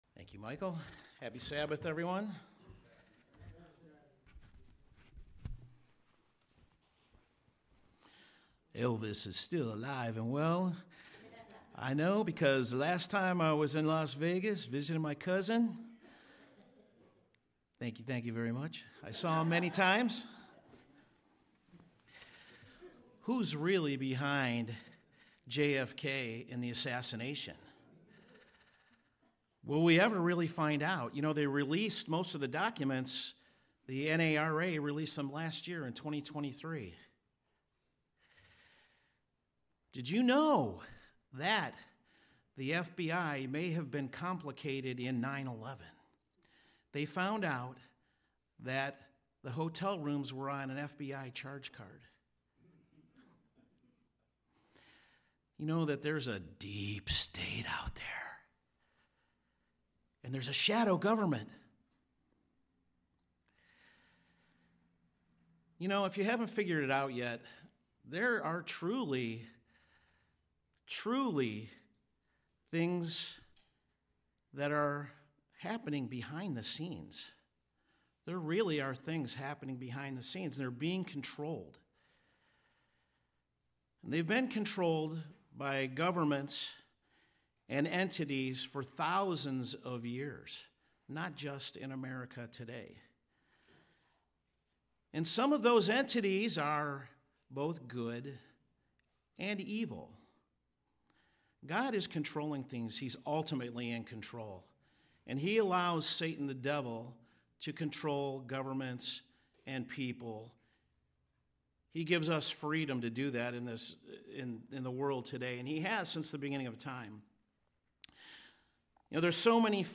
Sermons
Given in Cleveland, OH